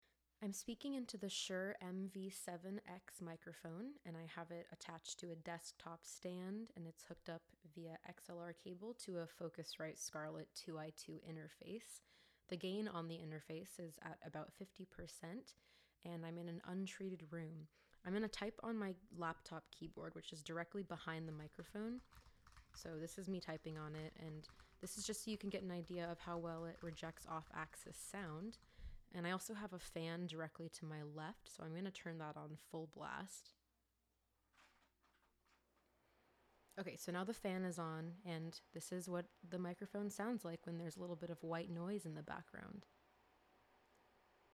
Shure-MV7X-speaking-microphone-sample-2.mp3